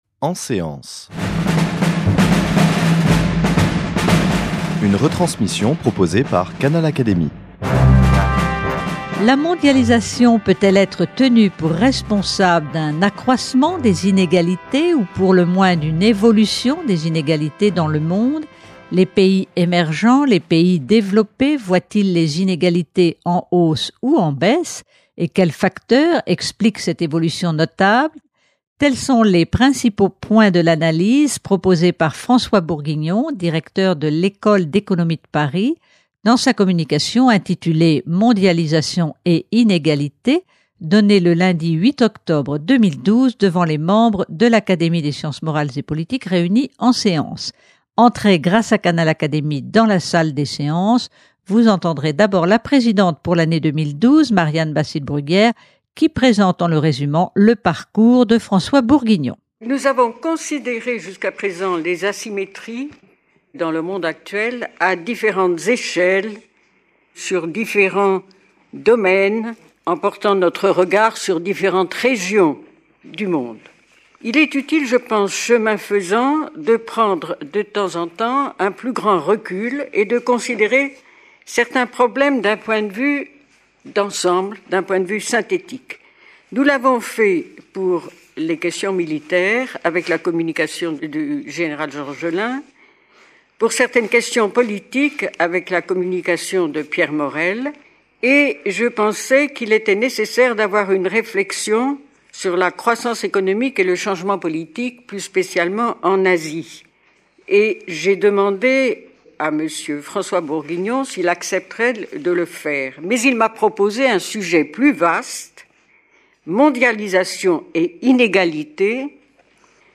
Il a donné sa communication le lundi 8 octobre devant les membres de l’Académie des sciences morales et politiques réunis en séance.